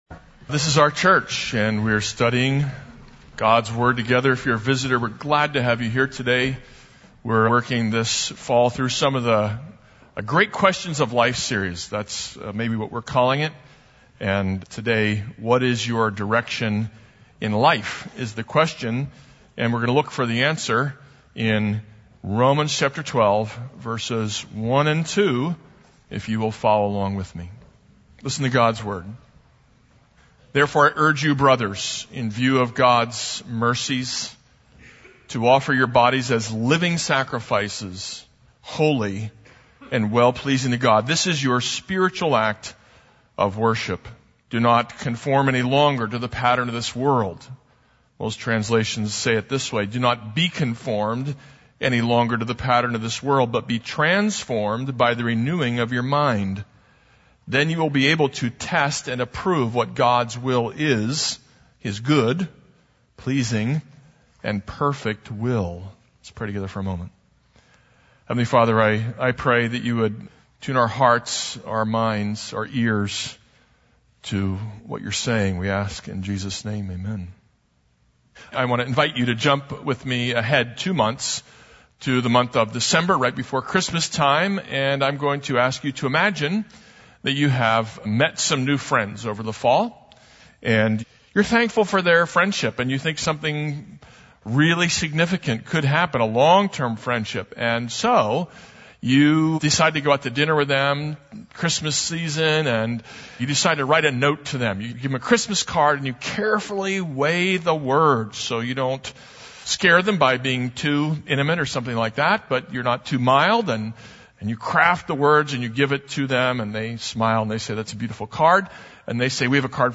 This is a sermon on Romans 12:1-2.